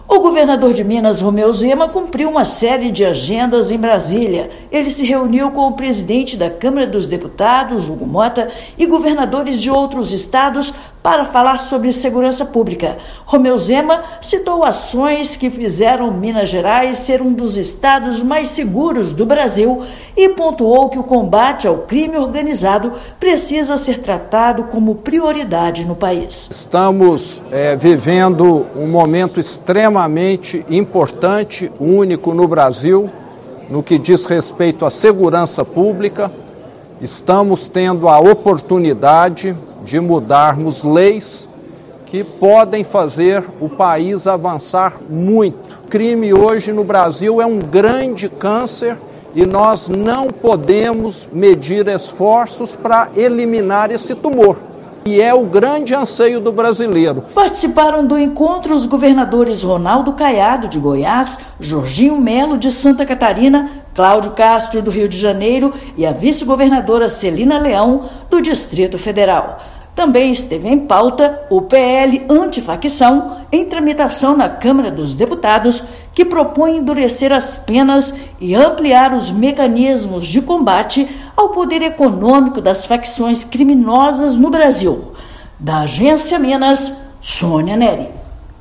Chefe do Executivo mineiro esteve com o presidente da Câmara dos Deputados e governadores. Ouça matéria de rádio.